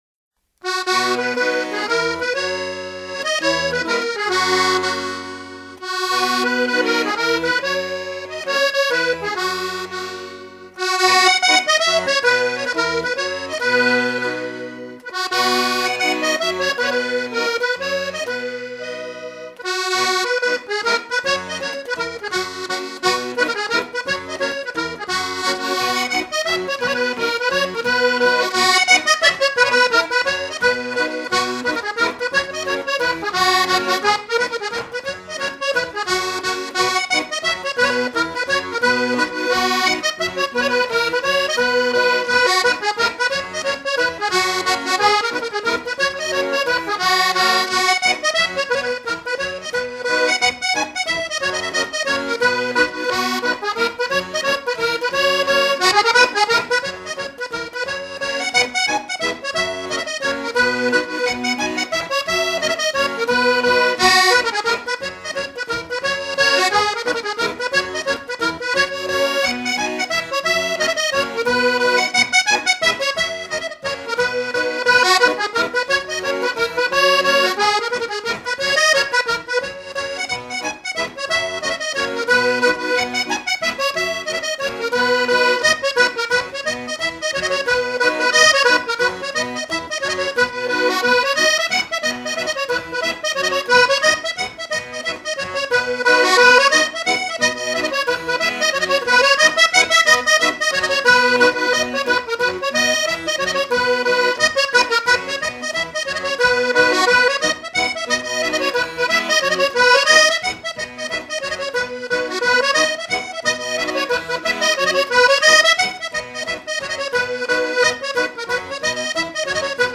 Panorama des traditions instrumentales
danse : gavotte bretonne